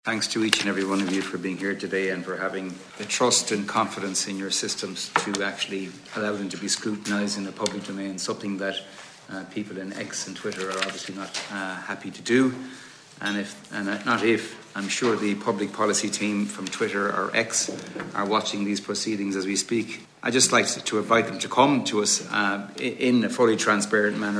Fine Gael TD for Galway East, Ciarán Cannon, says he's disappointed by X’s no show: